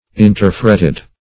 Search Result for " interfretted" : The Collaborative International Dictionary of English v.0.48: Interfretted \In`ter*fret"ted\, a. (Her.) Interlaced; linked together; -- said of charges or bearings.